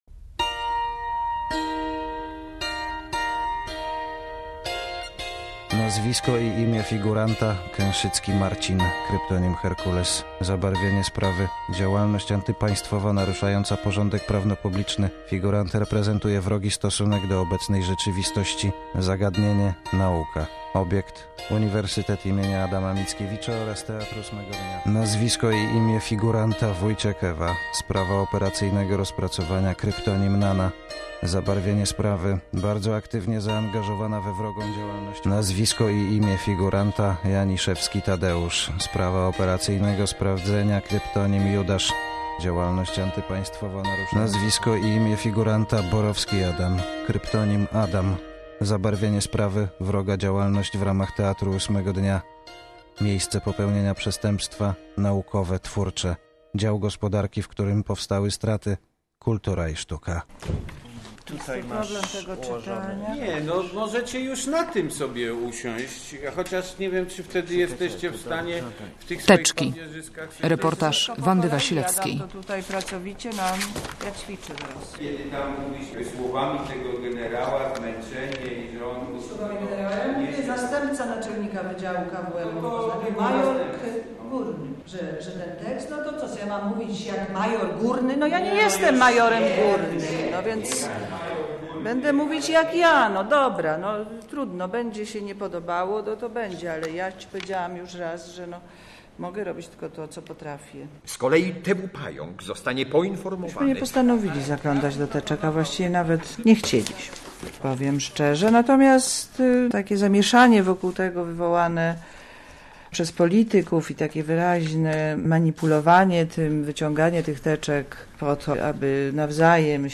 Teczki po latach - reportaż